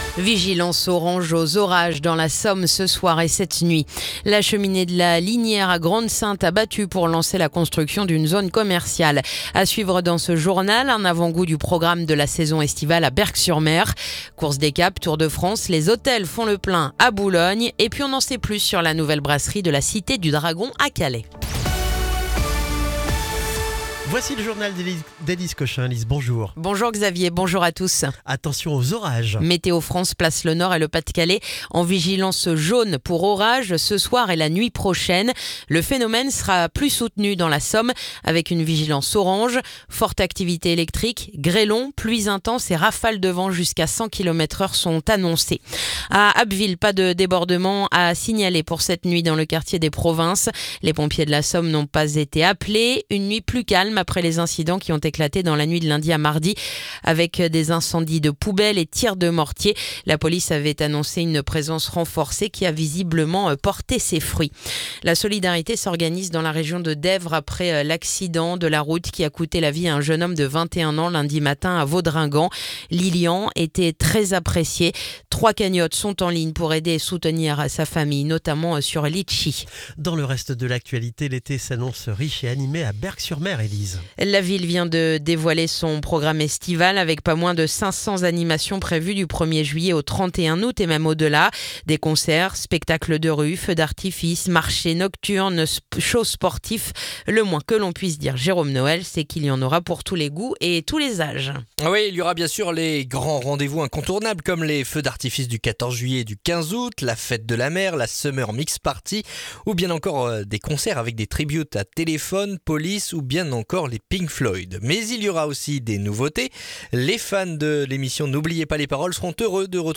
Le journal du mercredi 25 juin